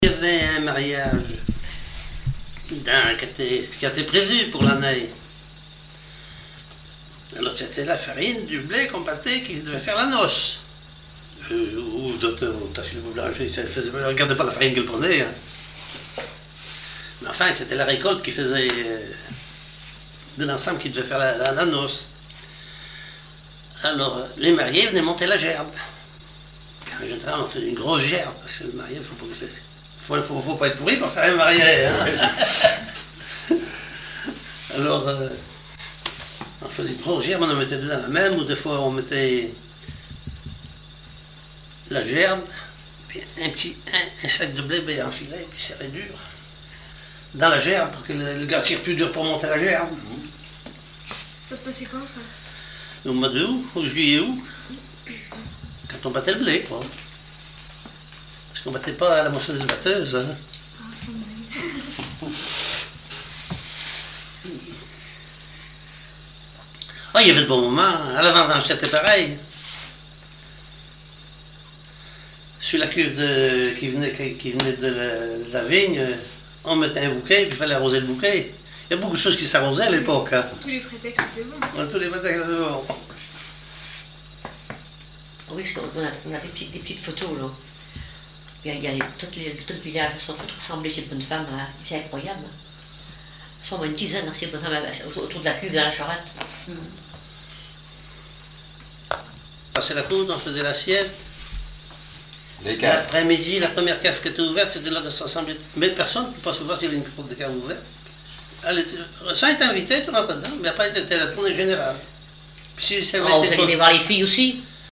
témoignage sur les noces
Catégorie Témoignage